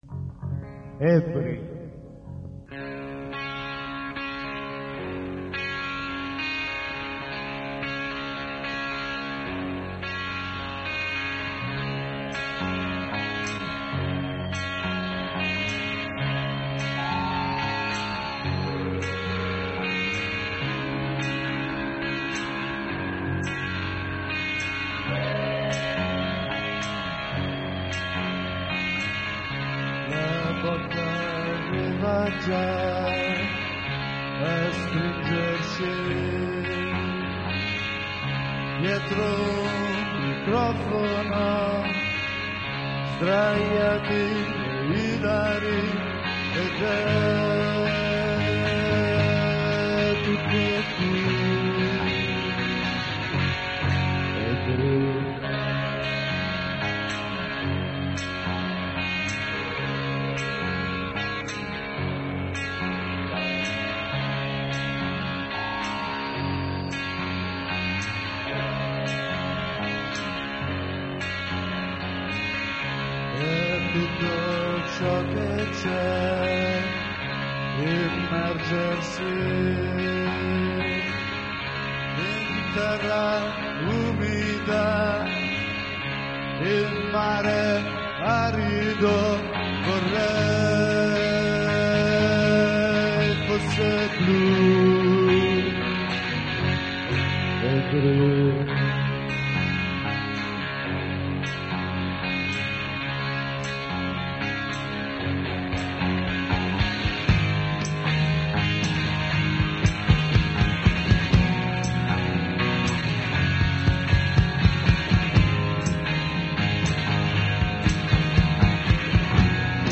alla chitarra
al basso
alla batteria